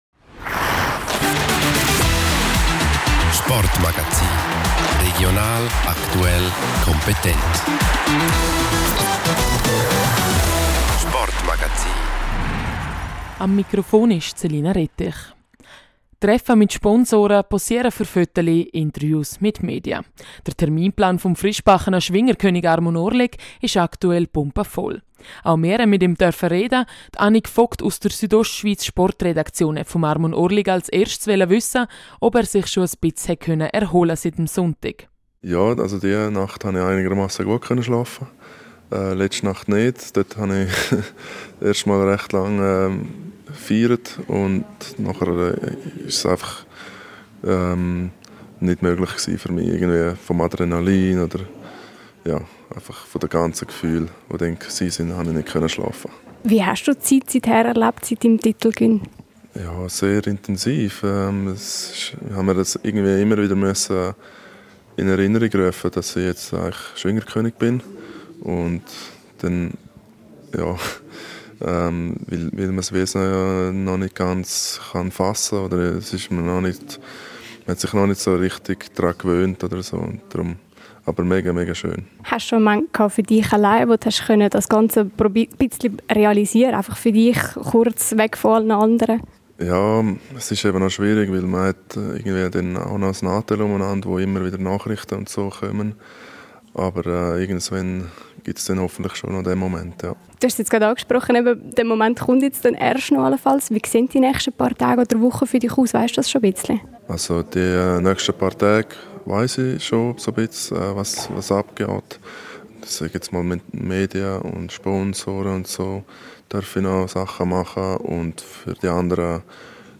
Der frischgebackene Schwingerkönig Armon Orlik trifft Sponsoren, stellt sich den Fragen der Medien und wurde schon zigfach für Zeitungen und Magazine abgelichtet. Auch für uns hat er sich Zeit für ein Interview genommen.